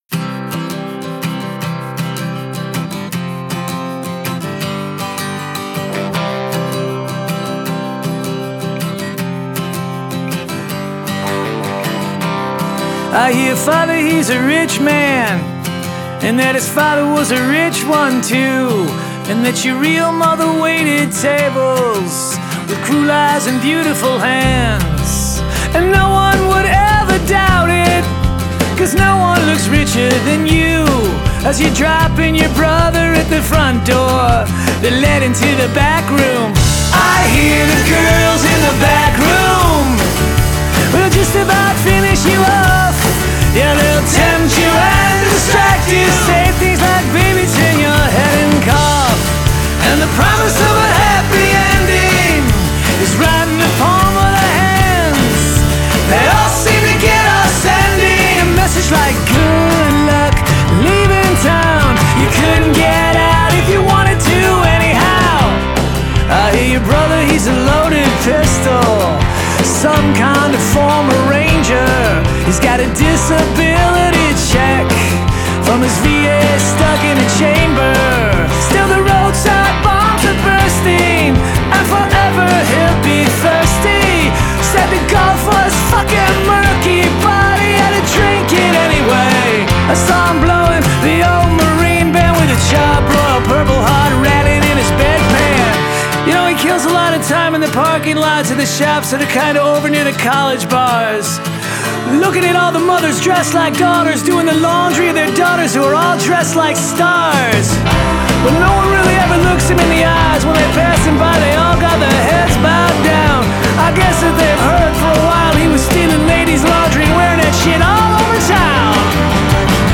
has a raucus sing-a-long quality